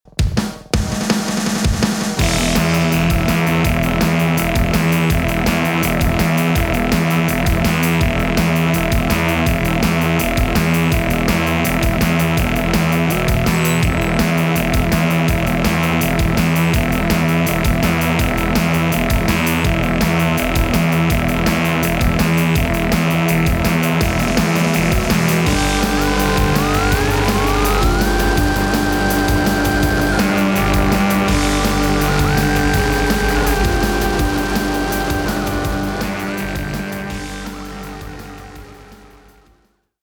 Género: Alternative / Experimental.